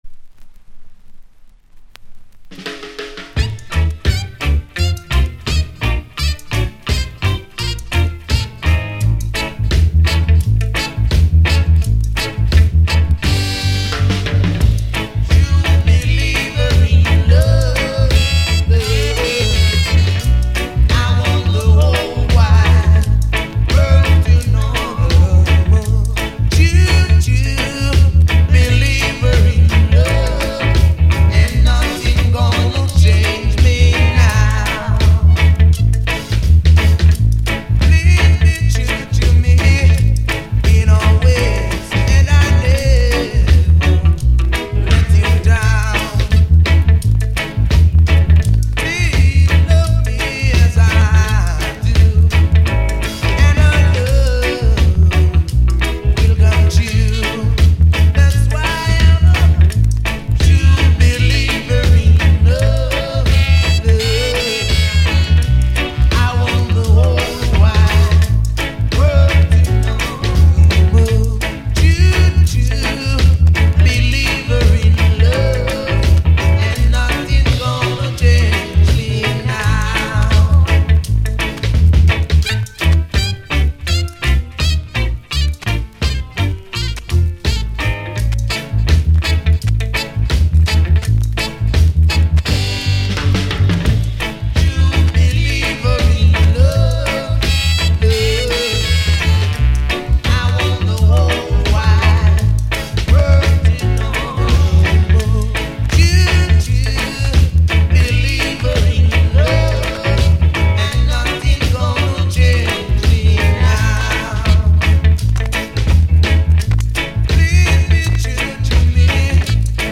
* 盤は綺麗ですが後半にザッと周期的なノイズ乗ります。